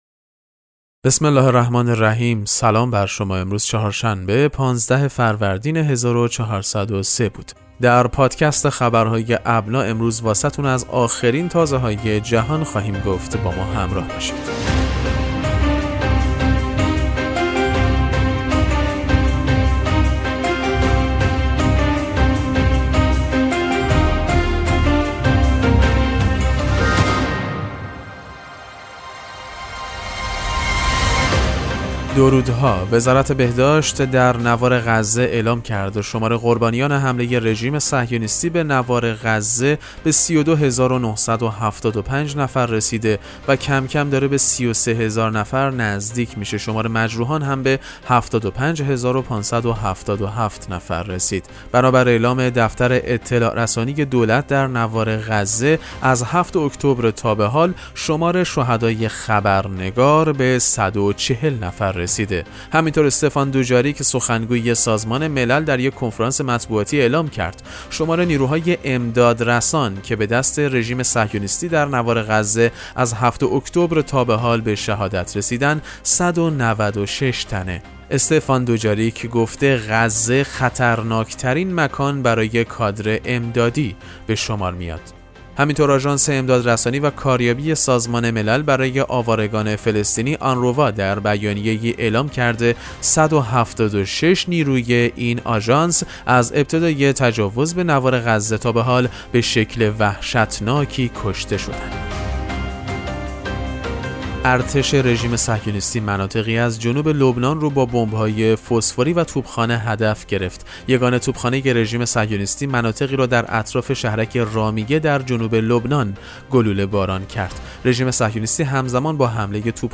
پادکست مهم‌ترین اخبار ابنا فارسی ــ 15فروردین 1403